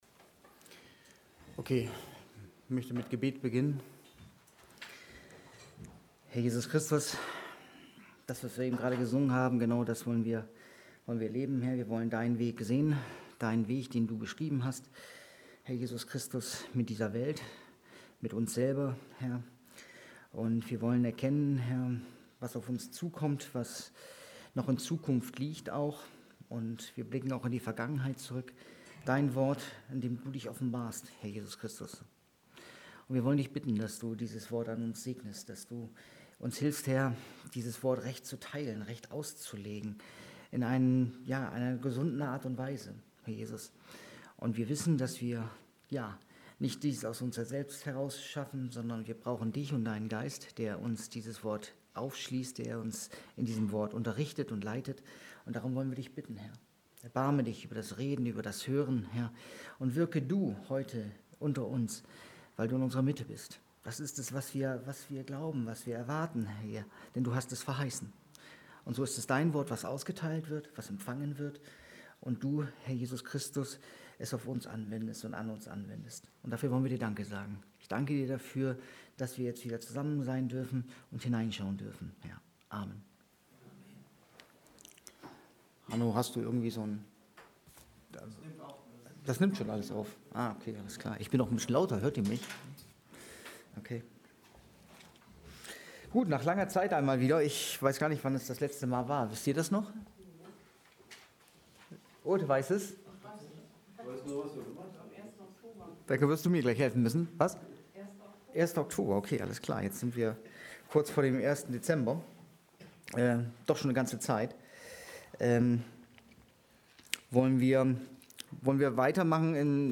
Bibelstunde 26.11.2020